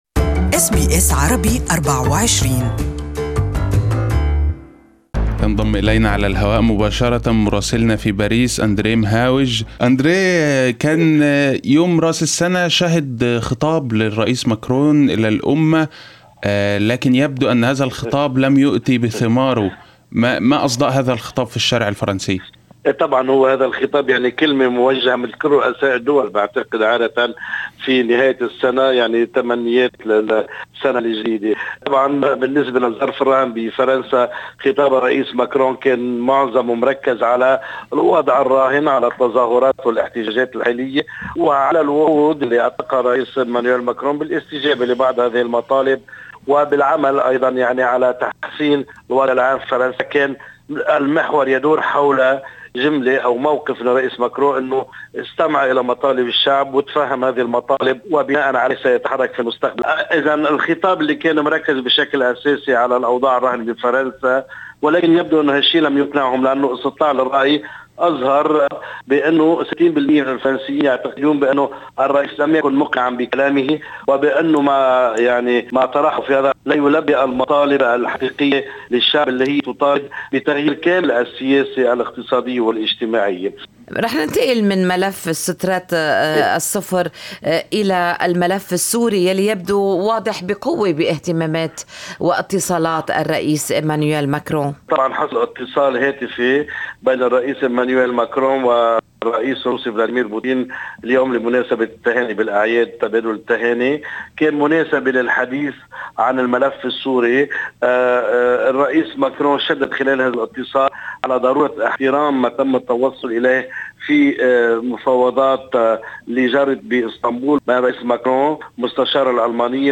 Our Paris correspondent
Listen to the full report from Paris in Arabic above